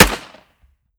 fps_project_1/45 ACP 1911 Pistol - Gunshot B 004.wav at ea26c77a496125fe026c6643cfebdd4452f9915f